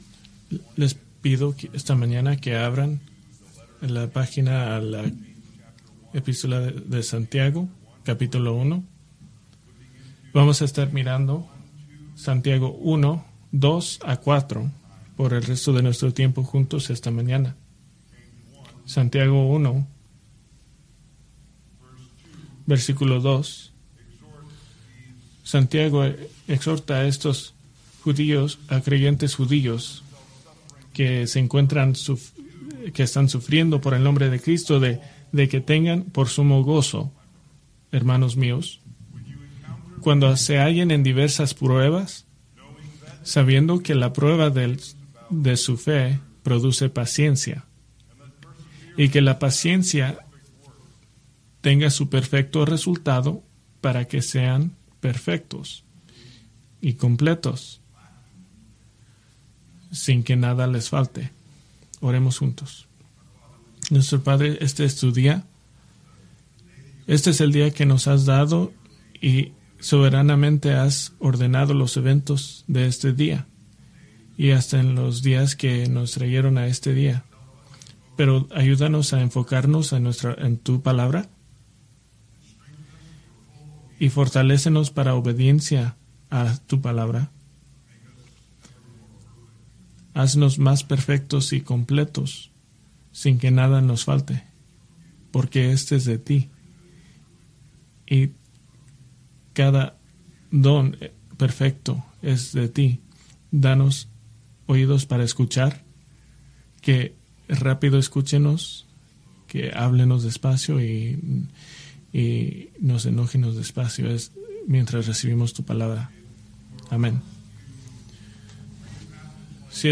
Preached July 14, 2024 from James 1:2-4